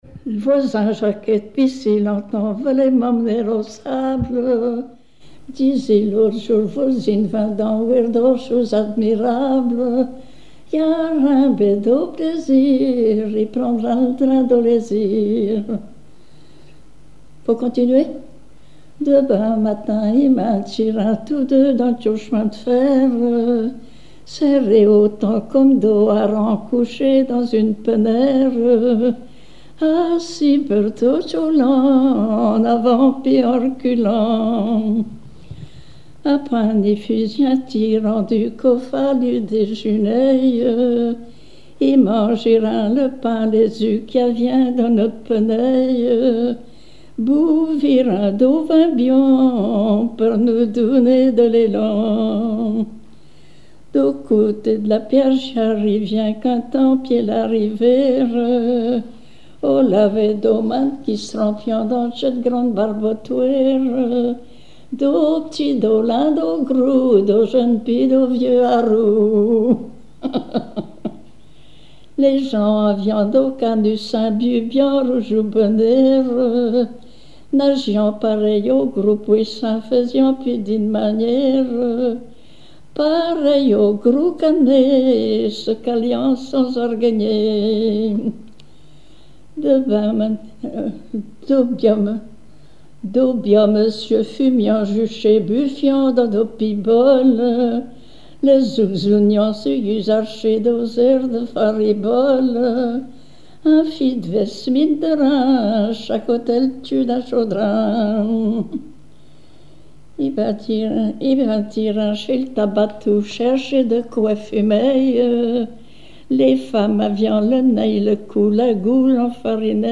Langue Patois local
Genre strophique
Chansons et témoignages
Pièce musicale inédite